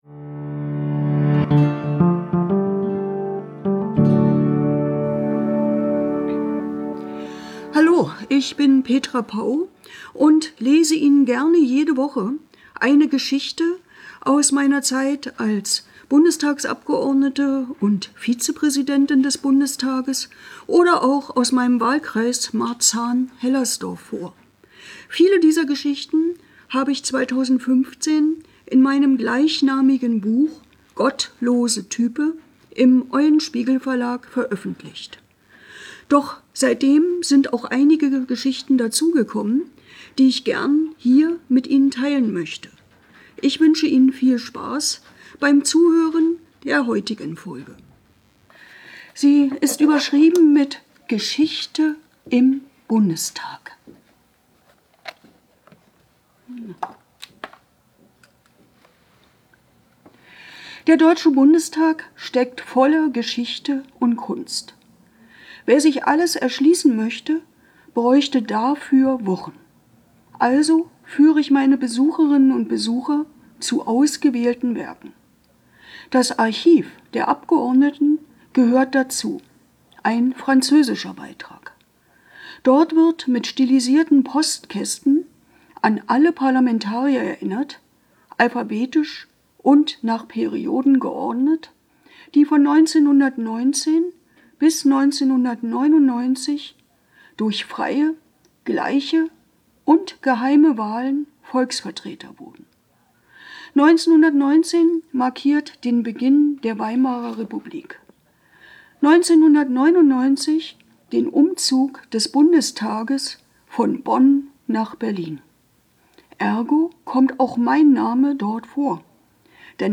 Um dem wenigstens ein klein wenig Abhilfe zu schaffen habe ich diesen Podcast aufgenommen um Ihnen regelmäßig kleine Episoden aus meinem Leben vorzulesen, welche ich in meinem Buch "Gottlose Type" festgehalten habe.
von und mit Petra Pau